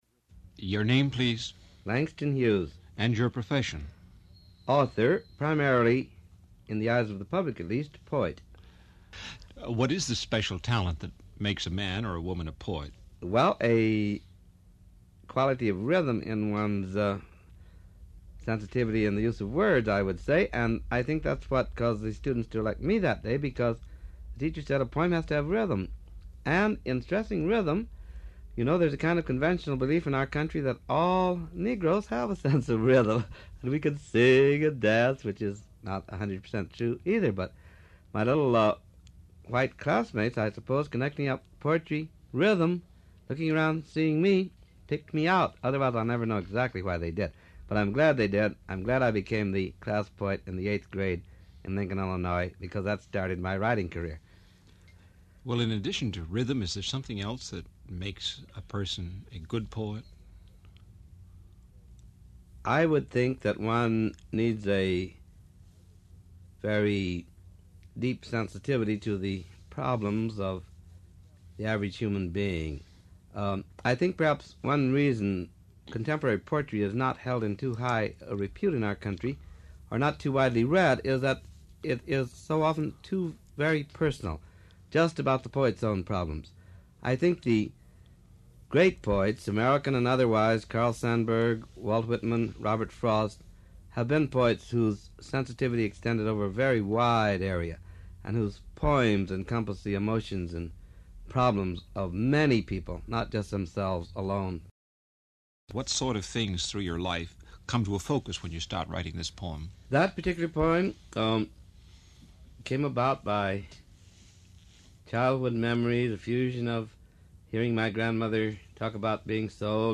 Title churchawakens347 Description Poet Langston Hughes reads two of his works, “The Feet of Jesus” and “My Lord,” and discusses the influence of spirituals on the rhythms of his poetry.